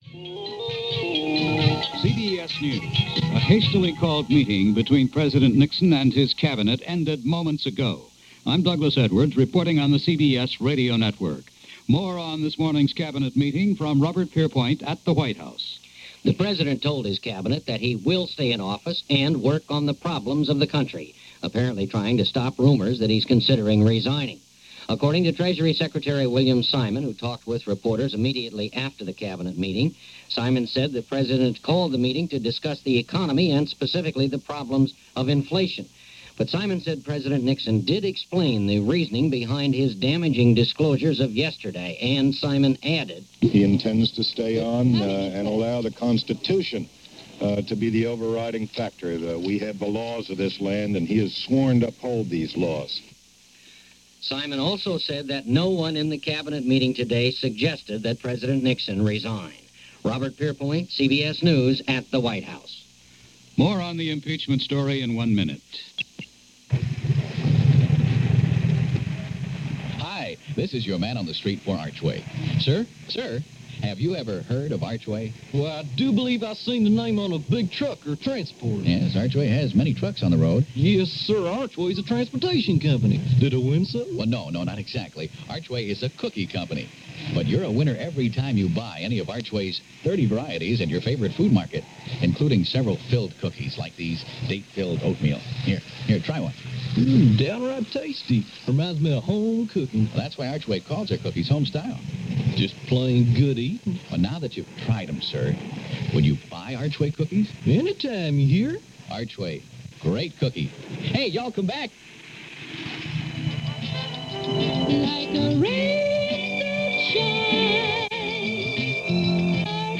News-August-6-1974.mp3